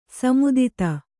♪ samudita